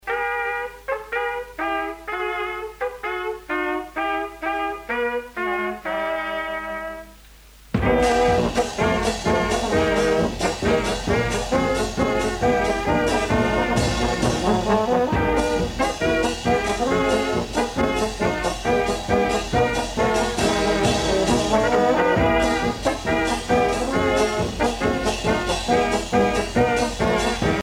Fanfare
Pièce musicale inédite